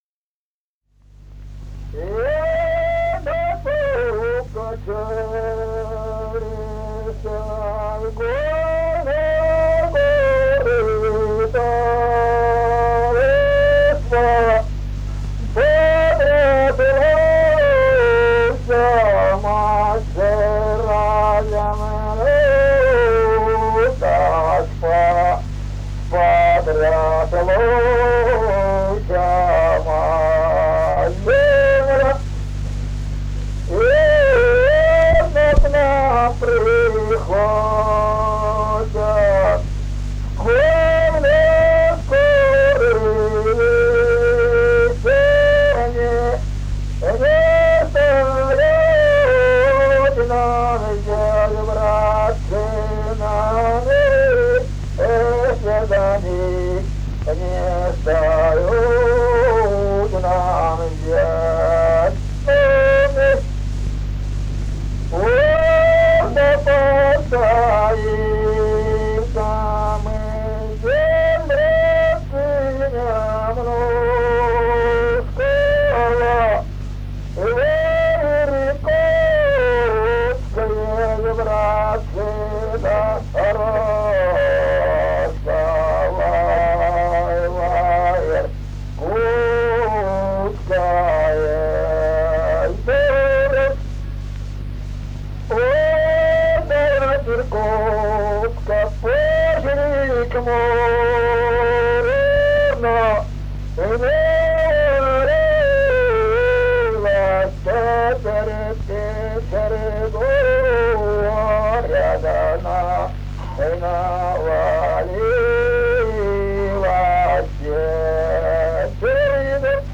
Русские народные песни Красноярского края.
Прим.: Сольное изложение песни, исполнявшейся в солдатском быту хором в два голоса.